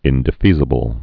(ĭndĭ-fēzə-bəl)